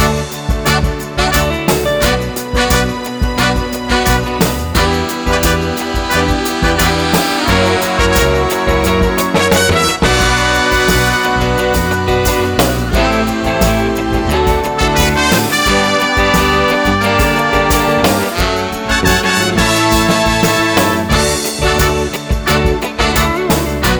Live Pop (1960s) 2:27 Buy £1.50